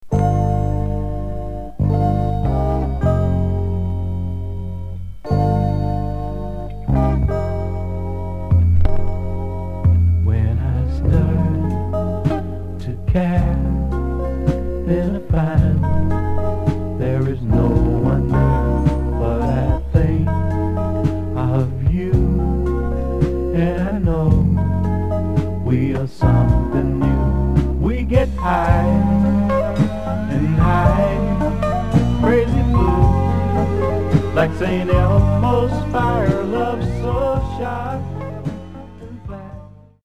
Genre: Soul/Funk